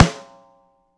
gretsch snare ff.wav